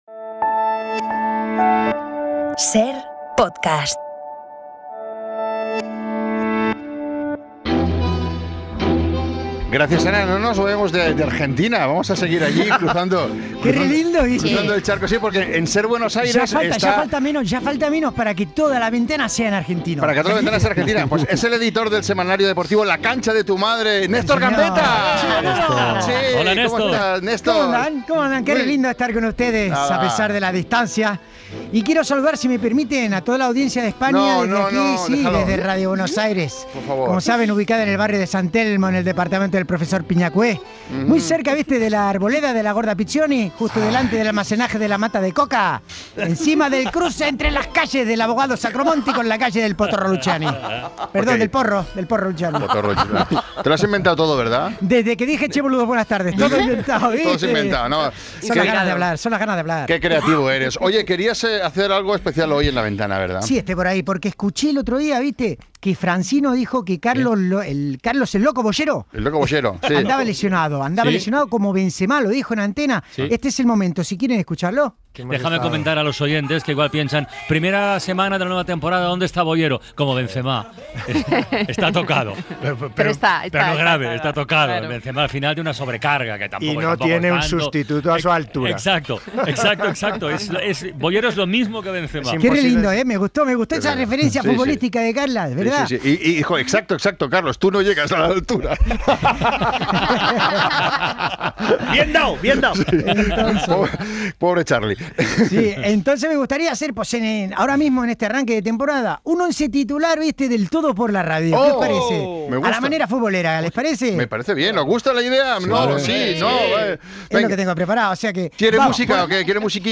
Néstor Gambetta, periodista argentino obviamente aquejado de histeria futbolera, nos canta en directo la alineación del Todo por la Radio. Un 4-3-3 de libro